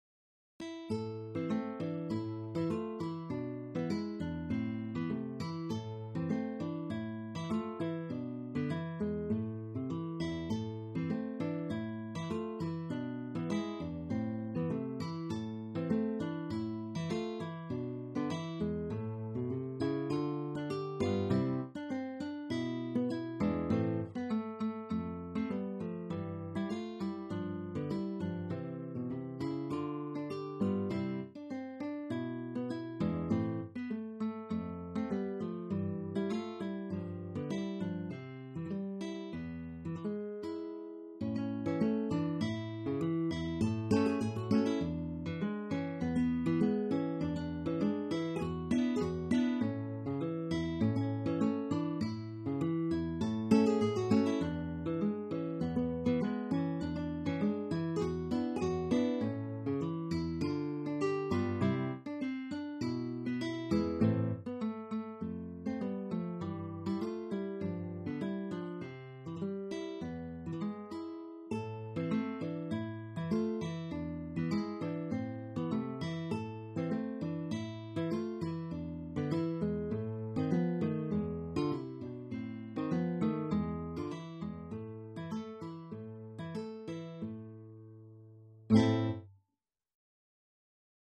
HABANERA
—>È una danza popolare dal ritmo lento e non rigido.